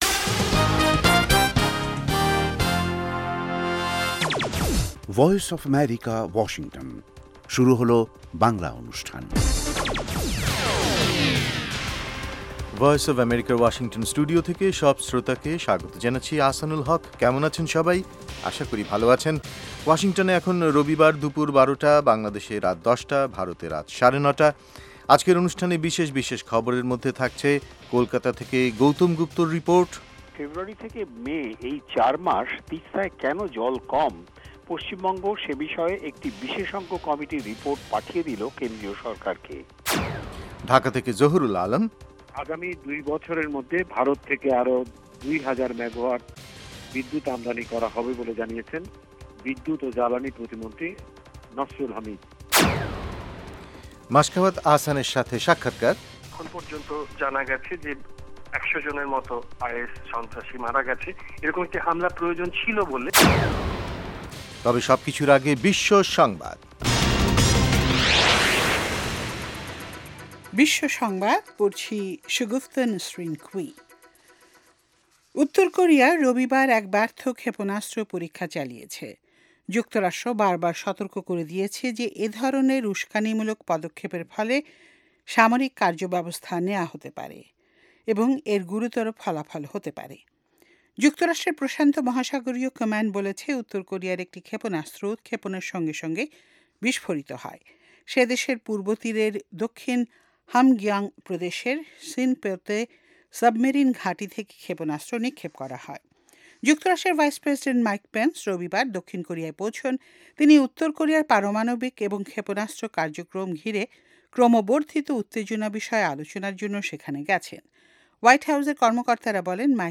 অনুষ্ঠানের শুরুতেই রয়েছে আন্তর্জাতিক খবরসহ আমাদের ঢাকা এবং কলকাতা সংবাদদাতাদের রিপোর্ট সম্বলিত বিশ্ব সংবাদ, এর পর রয়েছে ওয়ার্ল্ড উইন্ডোতে আন্তর্জাতিক প্রসংগ, বিজ্ঞান জগত, যুব সংবাদ, শ্রোতাদের চিঠি পত্রের জবাবের অনুষ্ঠান মিতালী এবং আমাদের অনুষ্ঠানের শেষ পর্বে রয়েছে যথারীতি সংক্ষিপ্ত সংস্করণে বিশ্ব সংবাদ।